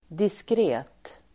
Uttal: [diskr'e:t]